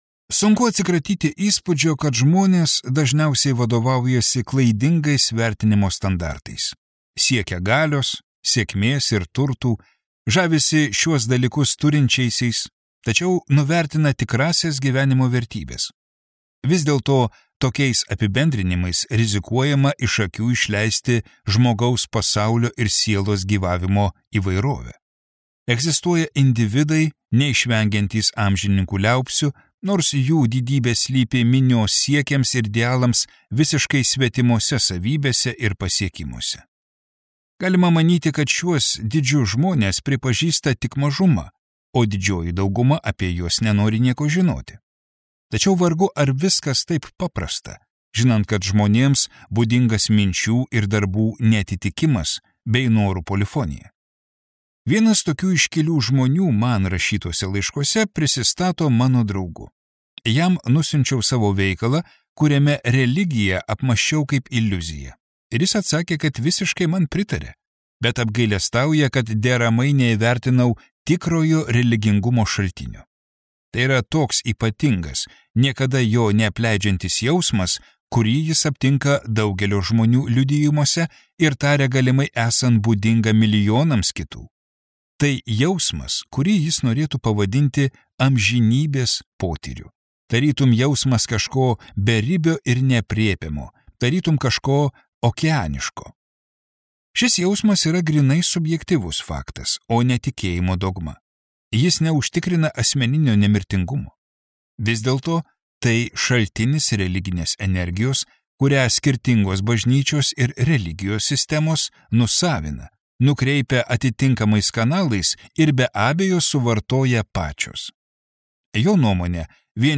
Nerimastis kultūroje | Audioknygos | baltos lankos